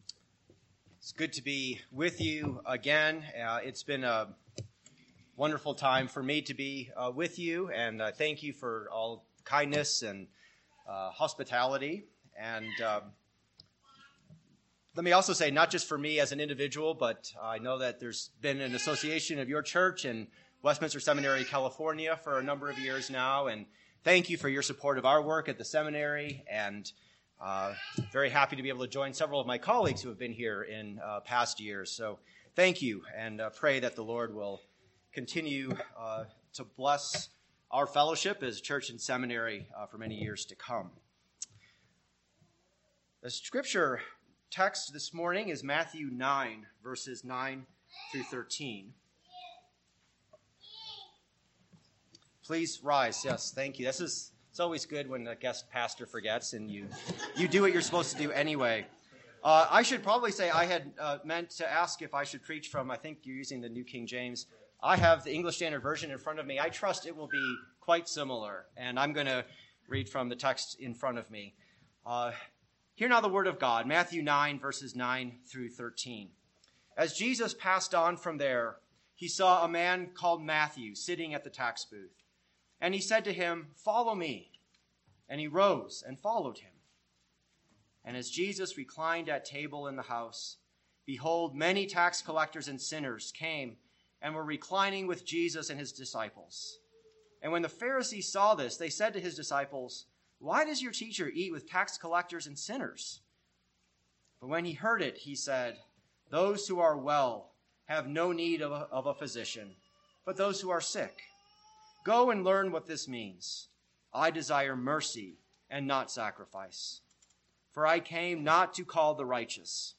Reformation Conference 2017 – Morning Worship
AM Sermon